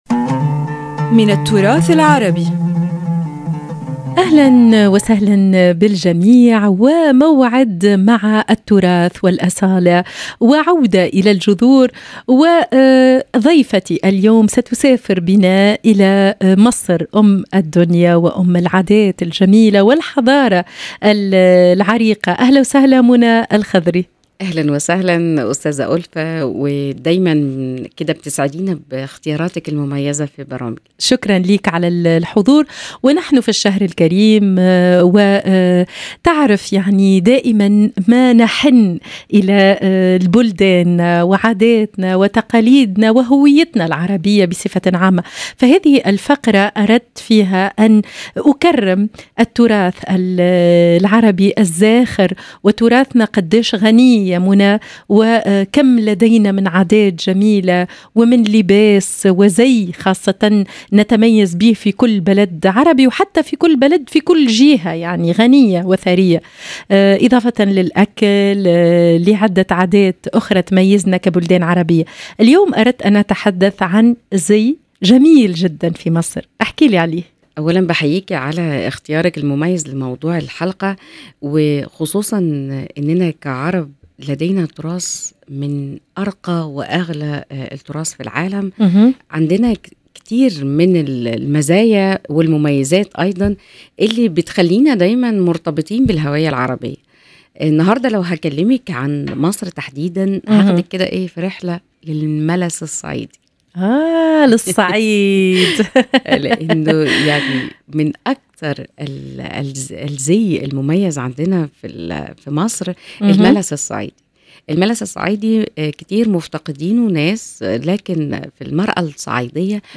وفي هذه الحلقة نستضيف الباحثة والمهتمة بالتراث